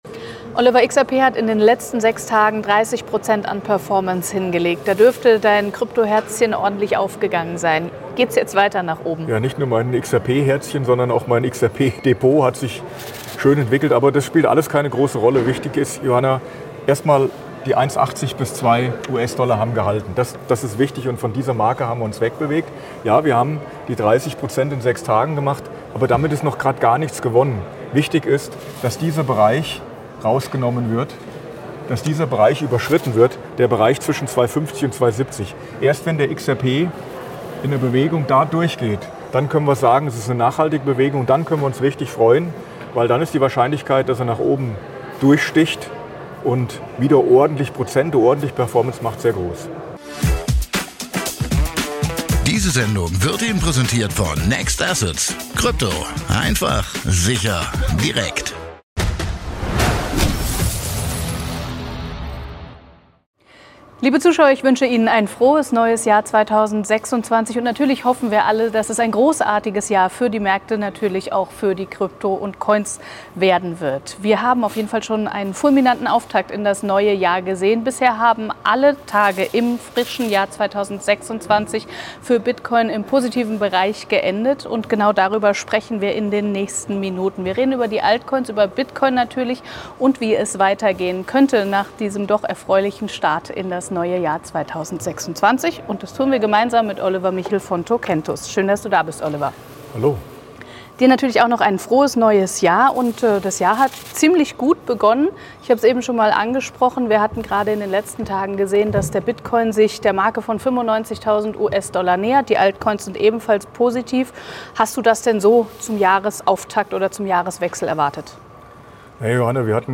Das Gespräch wurde am 05.01.2026 an der Frankfurter Börse aufgezeichnet.